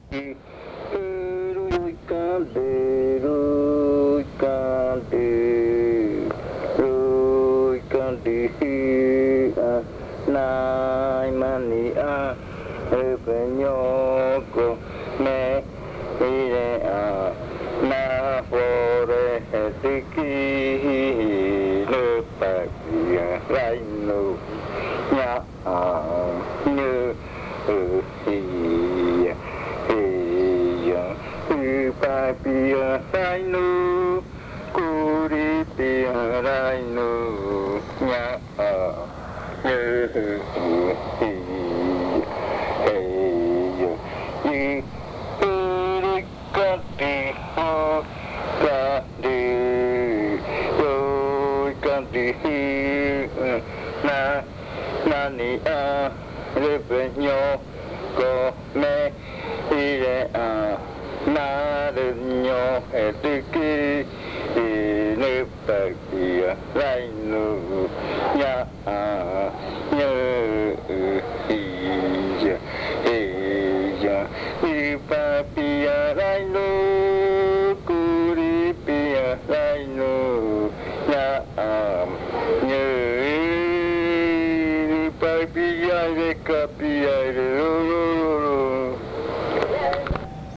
Leticia, Amazonas
Canto de arrrimada del baile de culebra (Jaiokɨ rua zɨjɨna).
Entry chant of the snake ritual
singer